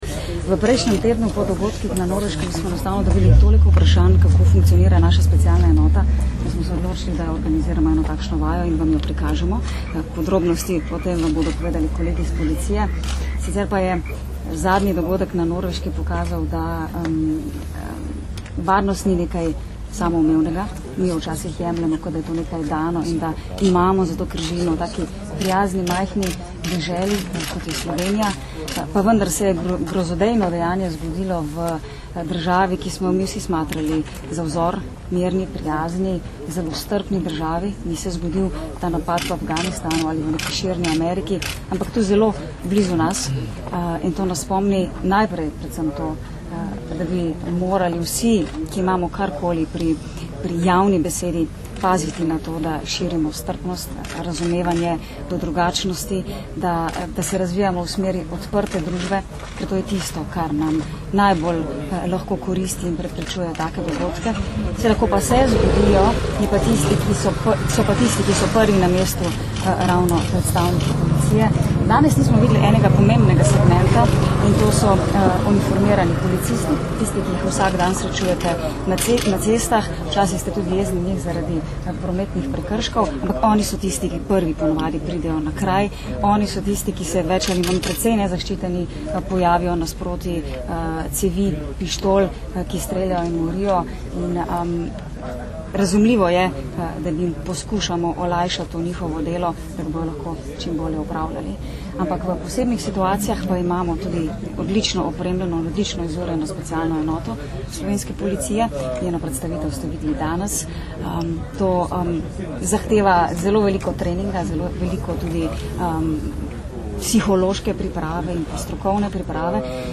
Izjava ministrice za notranje zadeve Katarine Kresal (mp3)